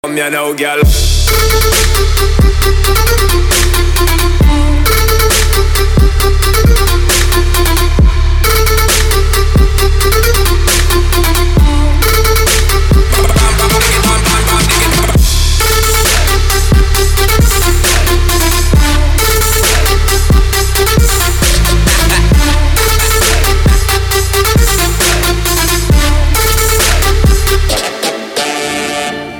Арабик трэпчик